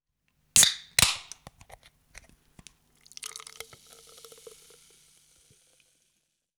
Bierdosenplopp
Das Öffnen einer Bierdose und anschließendes Einschenken, ein alltäglicher Vorgang für viele Menschen.
bierdose